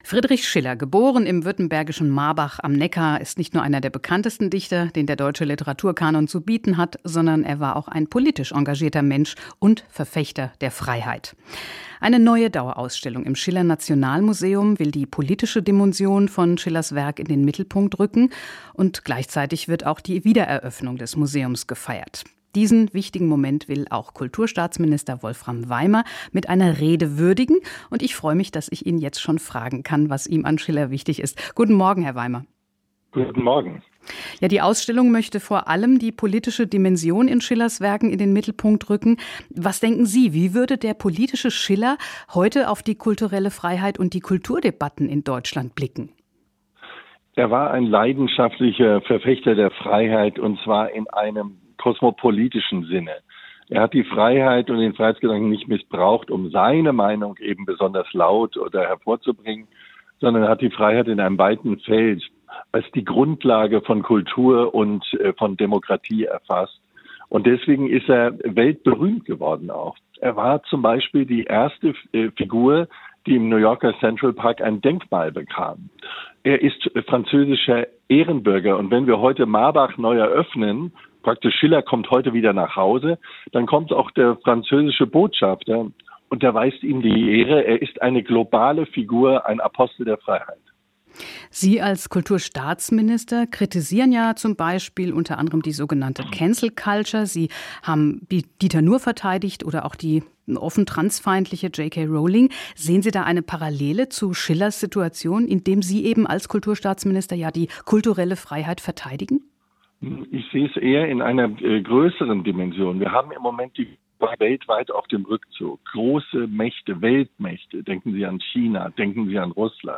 Die Wiedereröffnung des Museums feiert Kulturstaatsminister Wolfram Weimer mit einer Rede.
nach-sanierung-wiedereroeffnung-schiller-nationalmuseum-mit-rede-von-kulturstaatsminister-wolfram-weimer.mp3